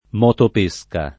motopesca [ m q top %S ka ]